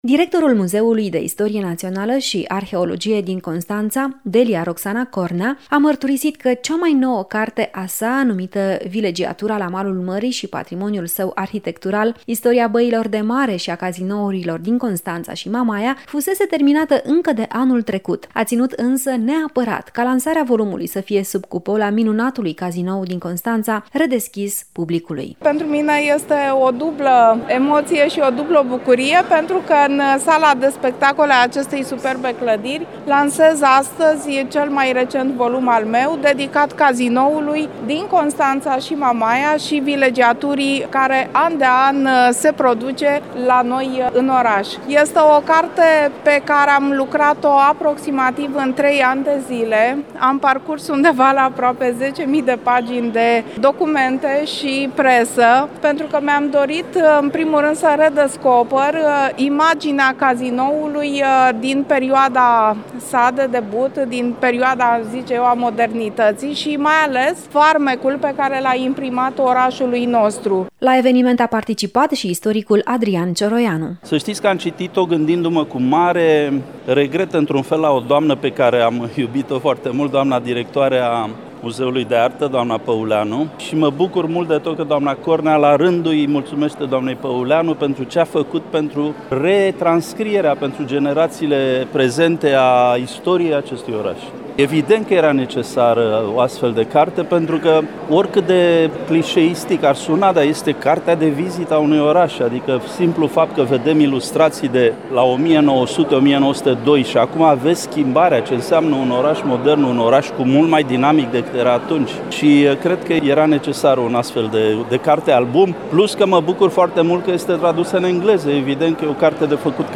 La eveniment a participat și istoricul Adrian Cioroianu, care a subliniat că acest volum poate fi cartea de vizită a orașului Constanța.